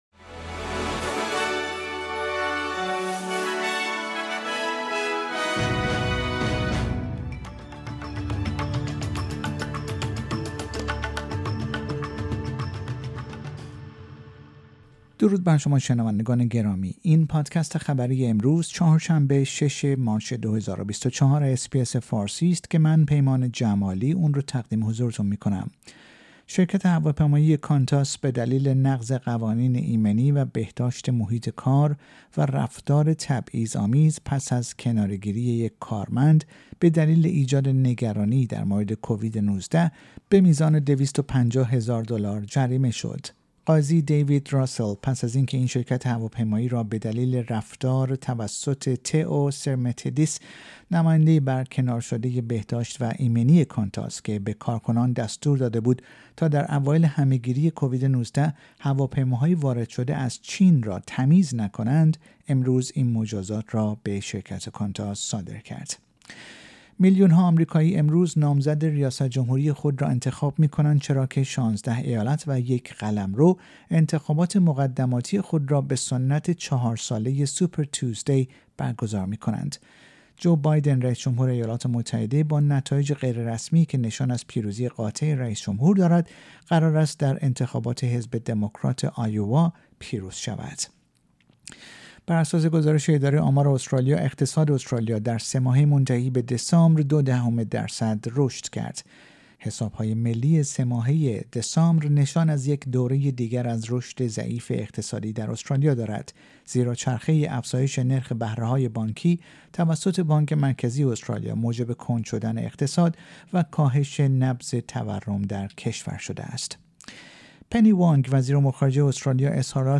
در این پادکست خبری مهمترین اخبار استرالیا و جهان در روز چهار شنبه ۶ مارچ ۲۰۲۴ ارائه شده است.